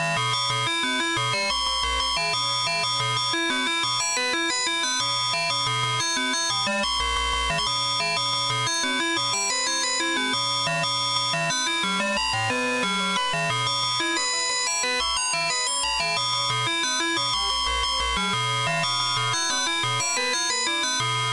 电耳警告破环（180bpm）